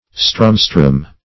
Search Result for " strumstrum" : The Collaborative International Dictionary of English v.0.48: Strumstrum \Strum"strum\, n. A rude musical instrument somewhat like a cittern.